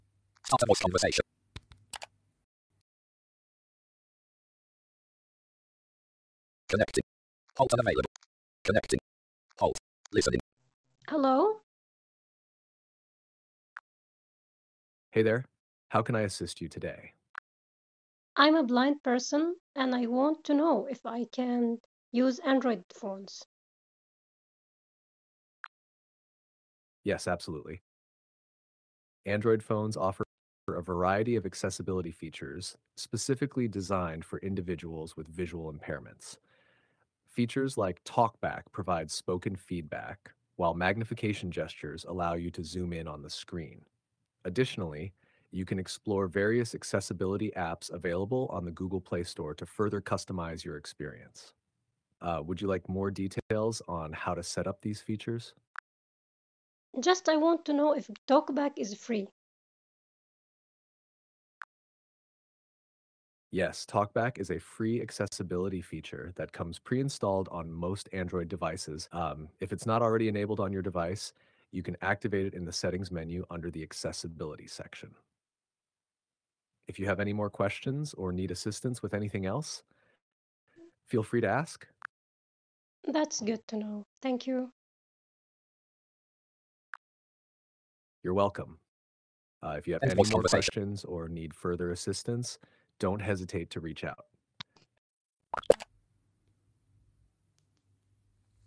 Audio demonstration
ChatGPT-Voice-Conversations-feature-demo.mp3